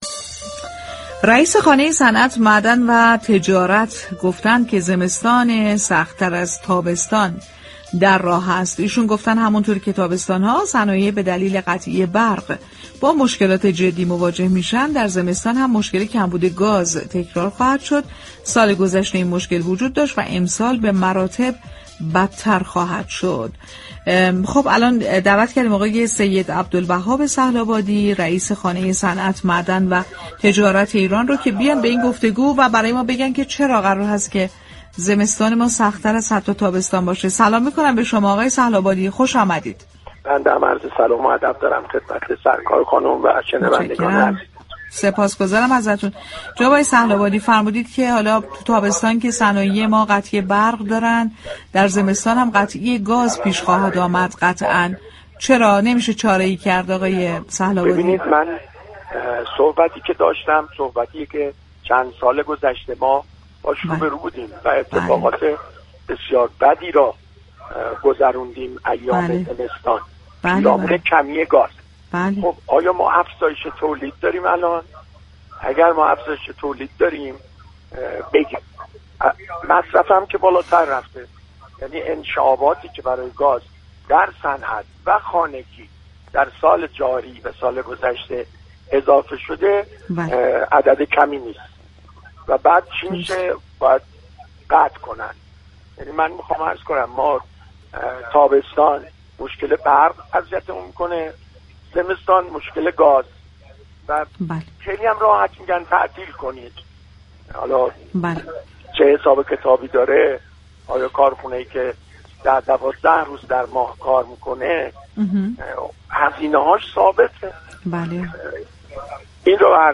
در گفت و گو با برنامه «بازار تهران» رادیو تهران